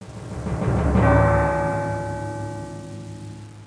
bell1.mp3